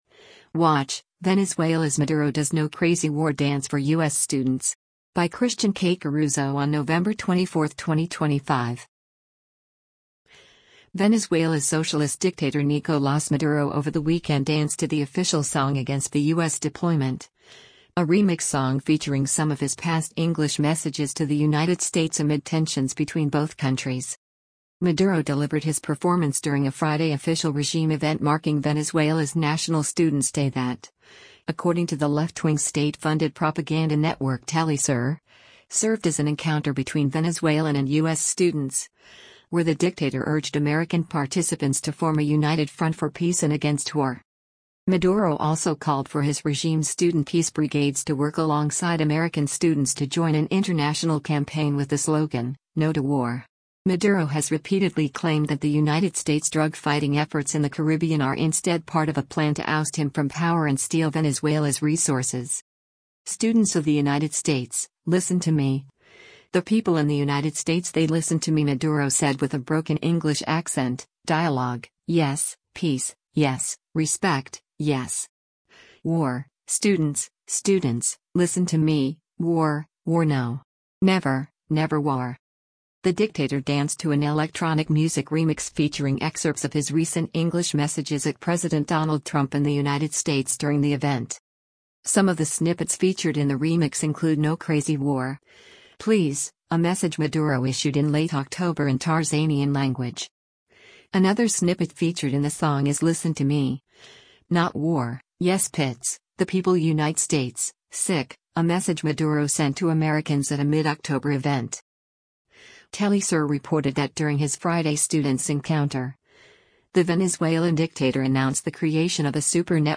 Maduro delivered his “performance” during a Friday official regime event marking Venezuela’s national students day that, according to the left-wing state funded propaganda network Telesur, served as an encounter between Venezuelan and U.S. students, where the dictator urged American participants to  “form a united front for peace and against war.”
“Students of the United States, listen to me, the people in the United States they listen to me” Maduro said with a broken English accent, “dialogue, yes, peace, yes, respect, yes. War, students, students, listen to me, war, war no. Never, never war.”
The dictator danced to an electronic music remix featuring excerpts of his recent “English” messages at President Donald Trump and the United States during the event.